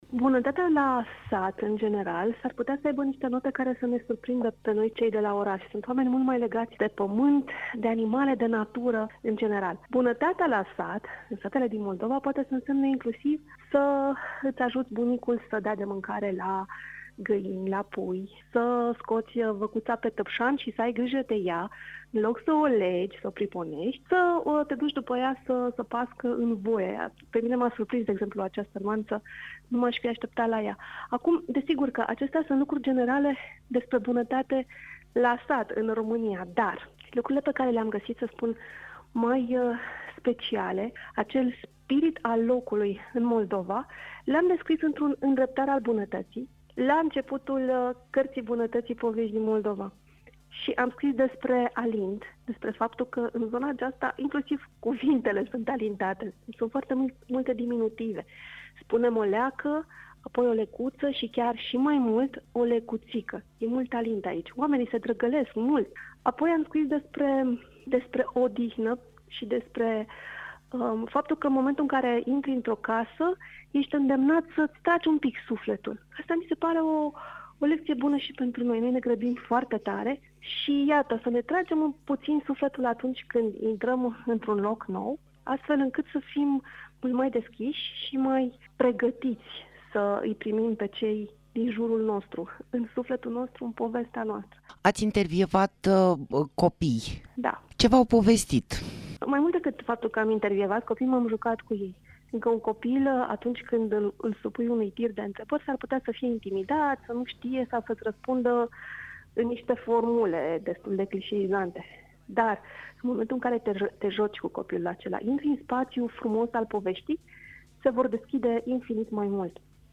Interviu-Cartea-bunatatii-din-Moldova.mp3